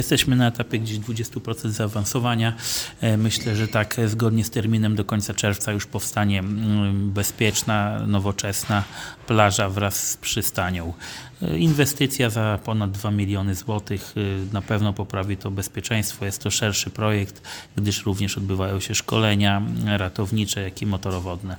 Jak mówi Robert Bagiński, wójt gminy Giby, całość ma być gotowa do końca czerwca.